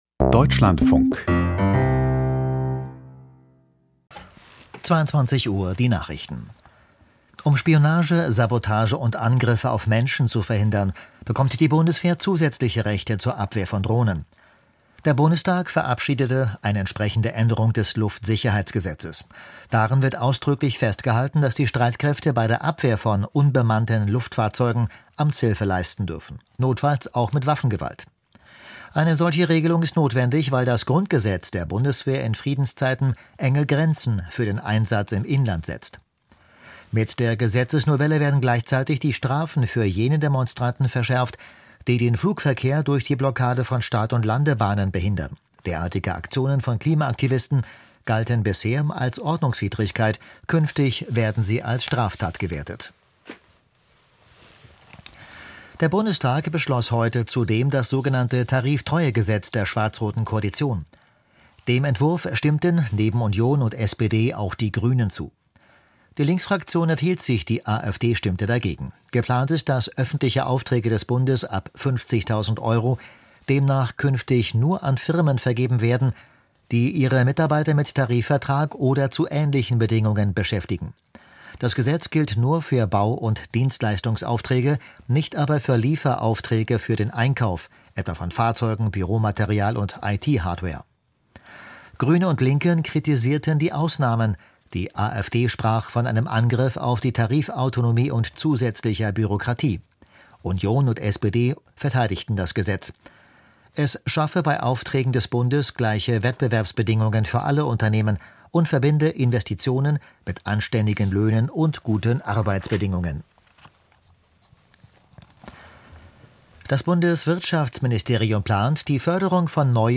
Die Nachrichten vom 26.02.2026, 22:00 Uhr
Die wichtigsten Nachrichten aus Deutschland und der Welt.
Aus der Deutschlandfunk-Nachrichtenredaktion.